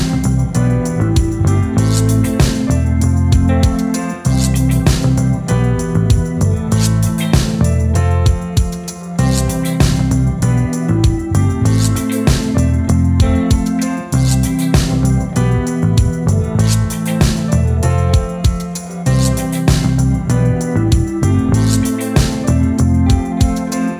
With no Backing Vocals Reggae